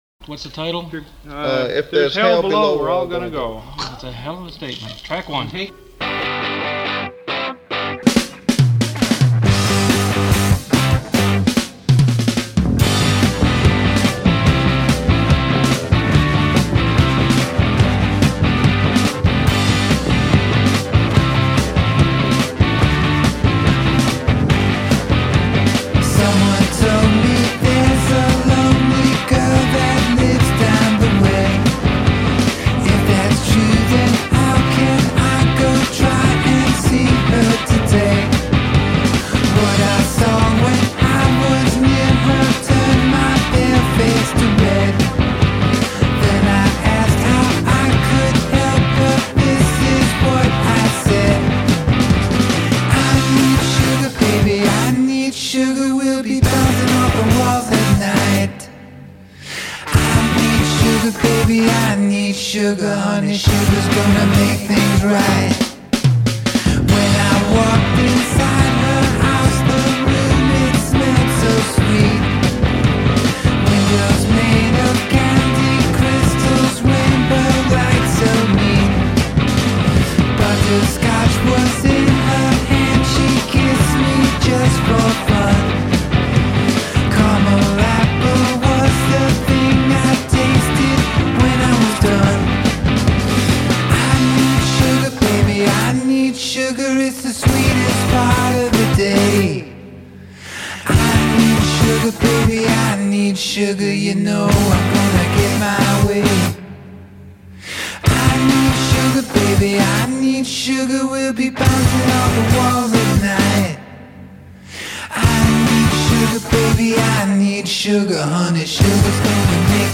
hip hop
nu-soul funk
country rock funk
power pop